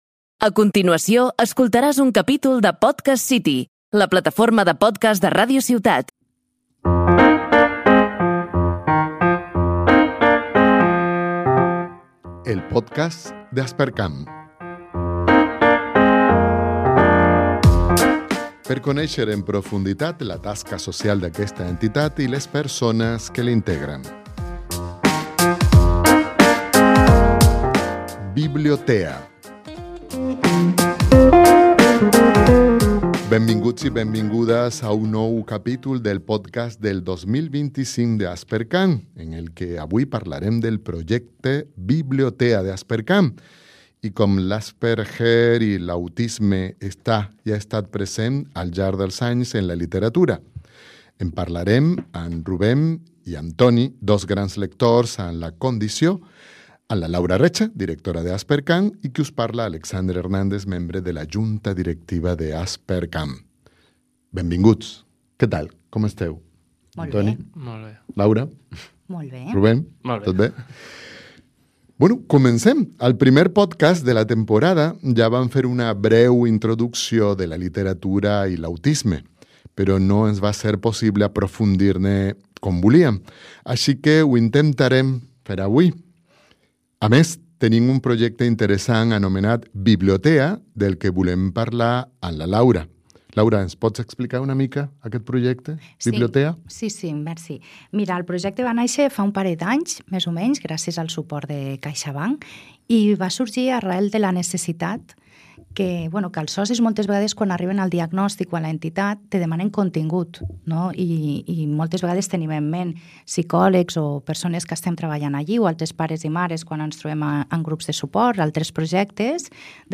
En el nou capítol d’Aspercamp descobrirem el projecte BiblioTEA així com l’Asperger-TEA està i ha estat present al llarg dels anys en la literatura. En parlem amb membres de l’entitat que tenen la condició en primera persona i familiars.